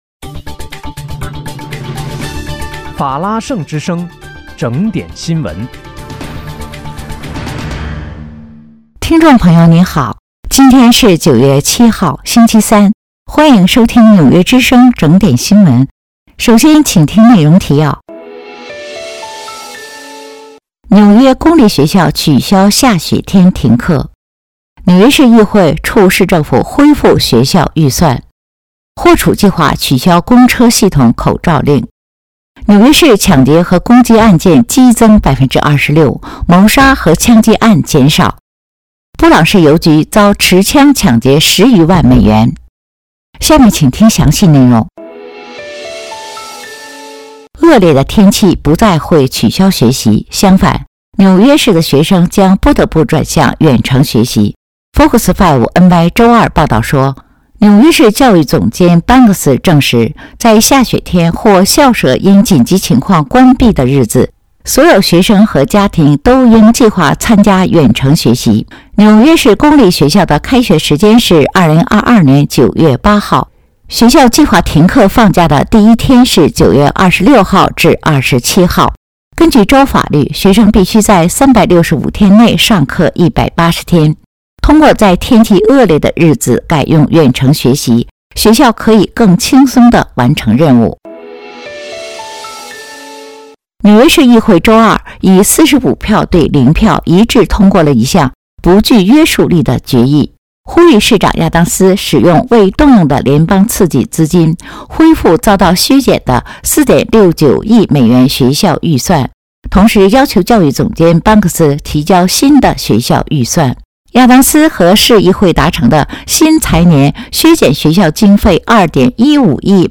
9月7日（星期三）纽约整点新闻